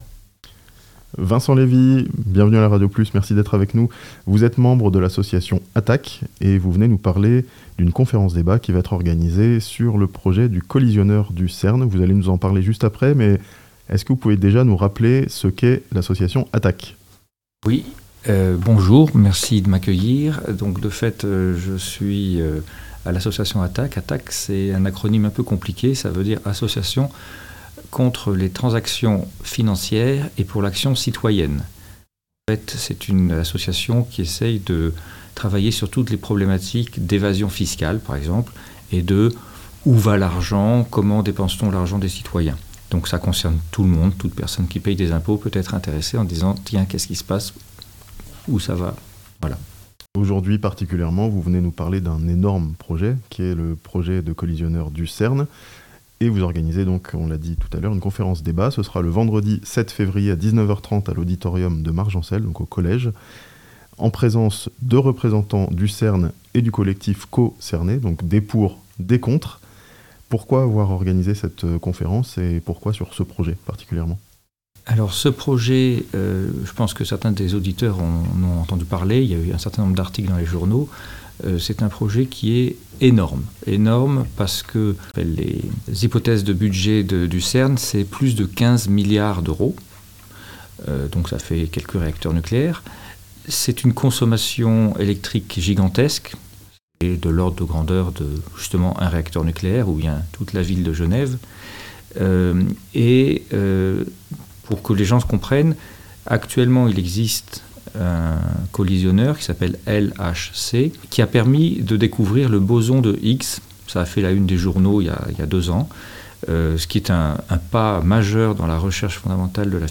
Une réunion débat à Margencel, sur le projet de collisionneur du CERN (interview)